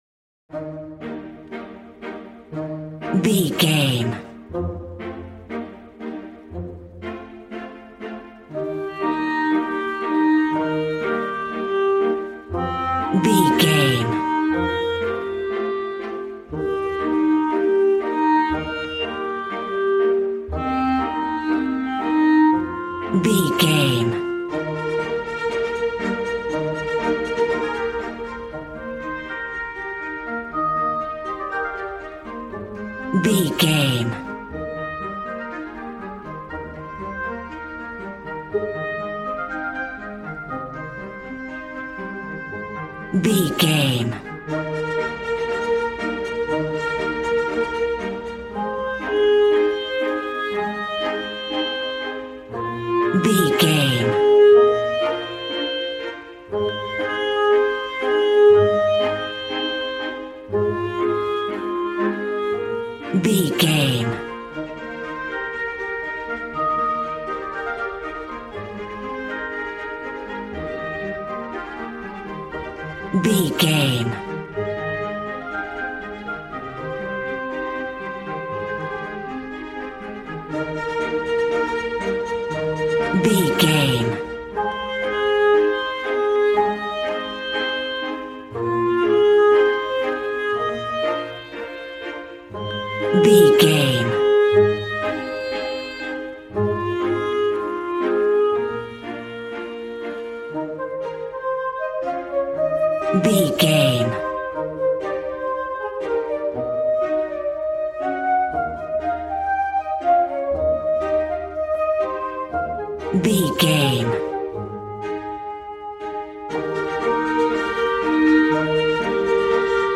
A warm and stunning piece of playful classical music.
Regal and romantic, a classy piece of classical music.
Ionian/Major
E♭
regal
piano
violin
strings